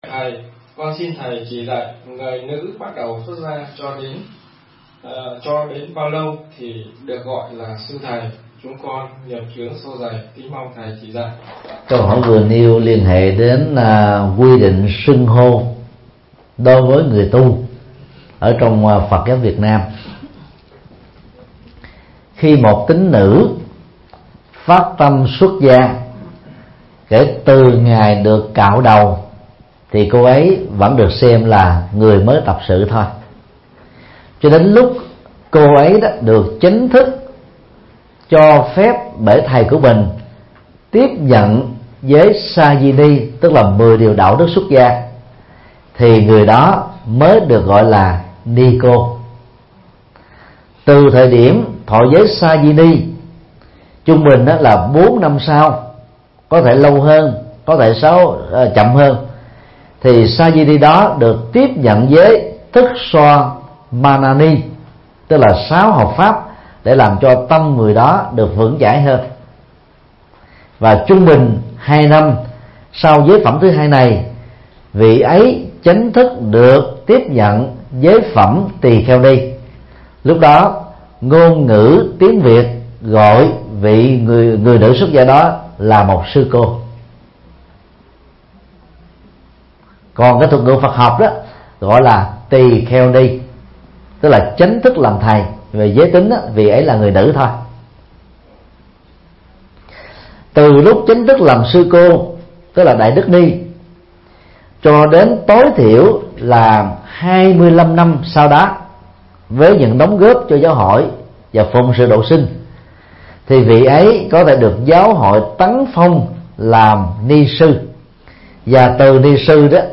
Vấn đáp: Danh xưng trong Phật giáo Việt Nam